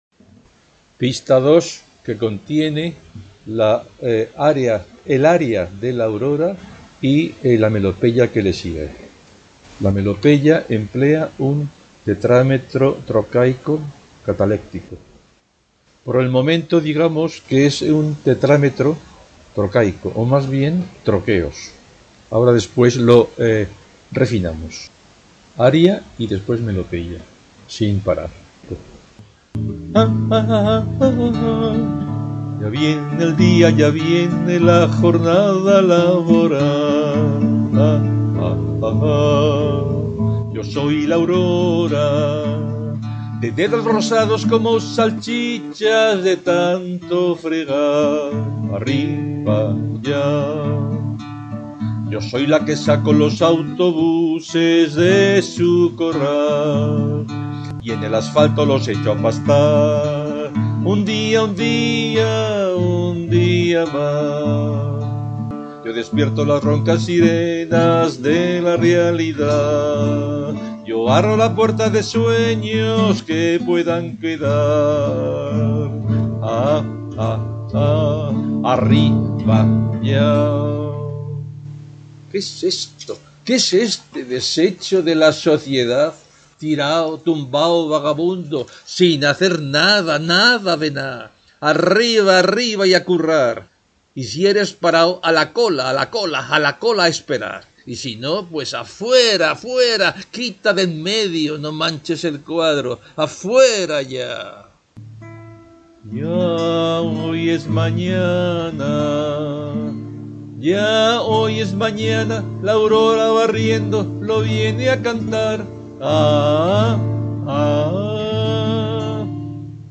Ya viene...'     4/25 canción 4/4   Ya viene eldIa..
2 2 'Qué es esto?...'           4/26 melopeya T8tc